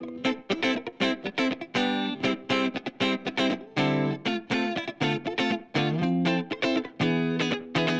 31 Rhythm Guitar PT1+2.wav